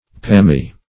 Palmy \Palm"y\, a.